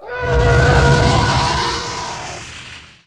roar1.wav